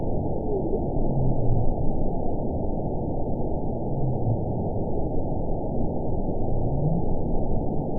event 921273 date 05/06/24 time 01:15:52 GMT (12 months ago) score 9.66 location TSS-AB08 detected by nrw target species NRW annotations +NRW Spectrogram: Frequency (kHz) vs. Time (s) audio not available .wav